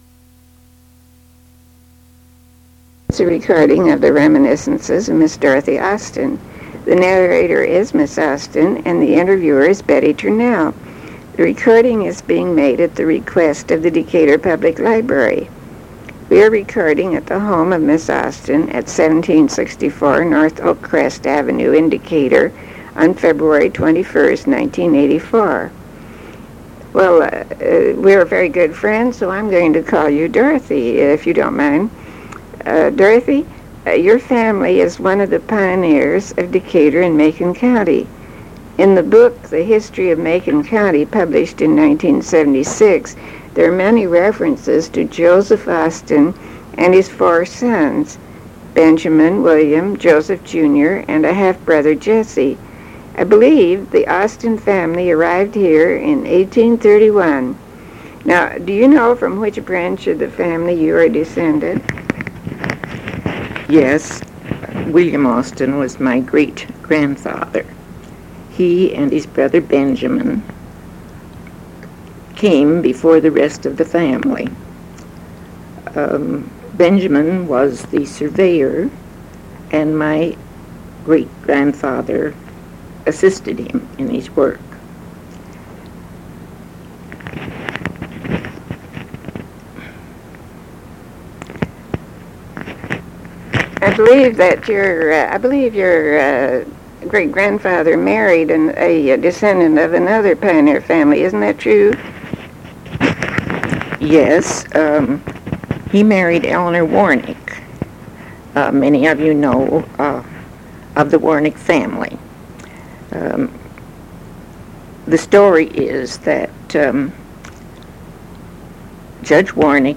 interview
oral history